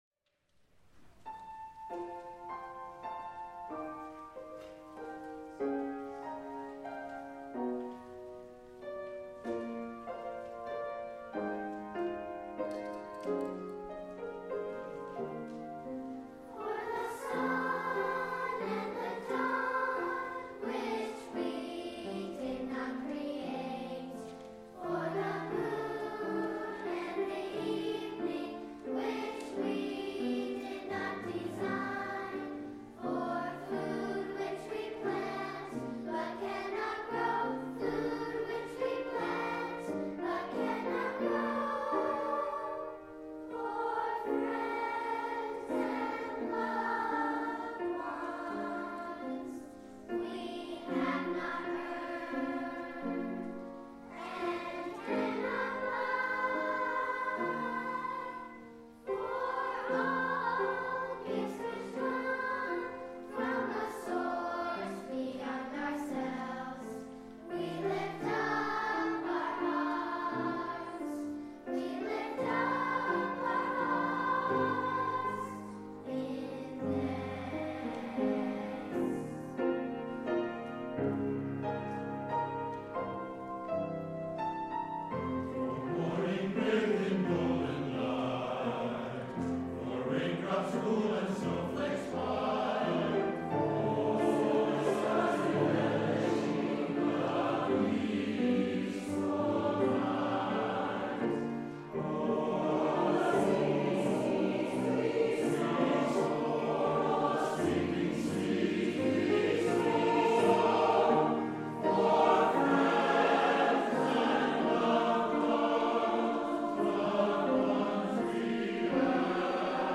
SATB + unison children’s choir, piano